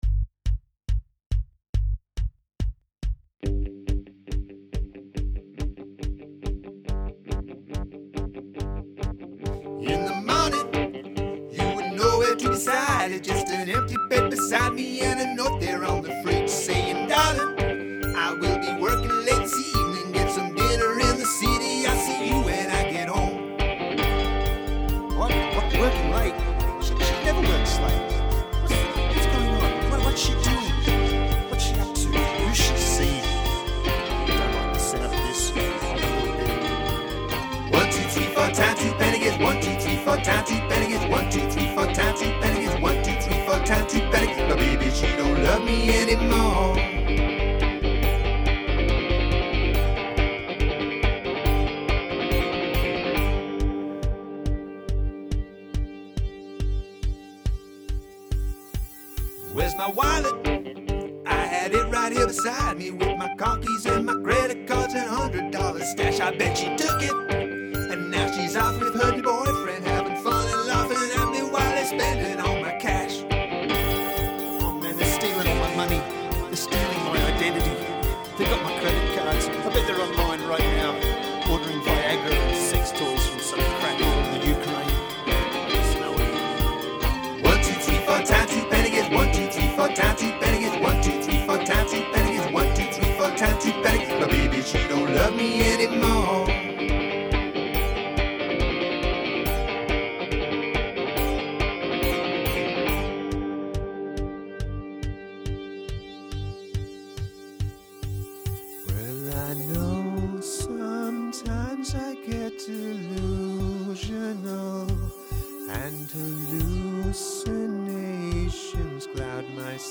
Song must include audible counting